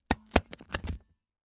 fo_stapler_01_hpx
Papers are stapled together. Paper, Staple